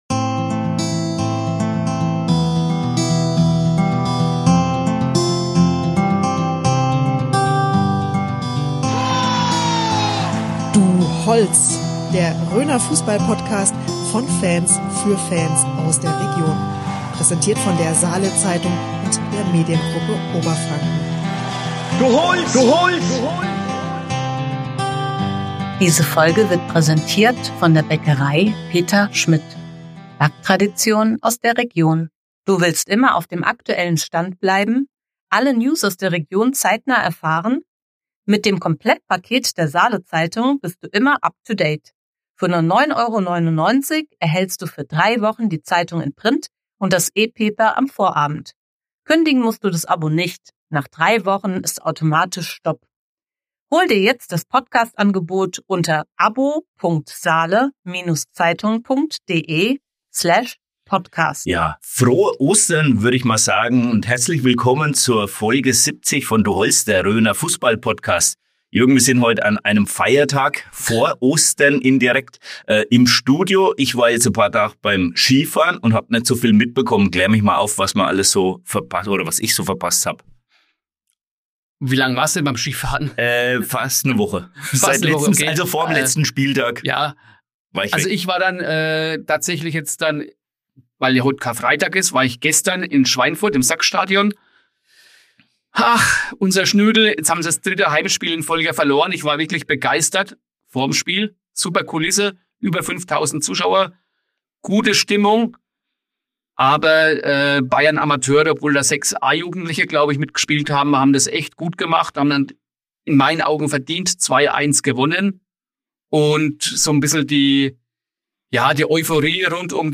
Die Podcast-Moderatoren